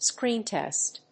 アクセントscréen tèst